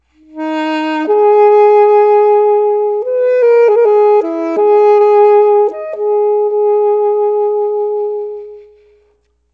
altosax.mp3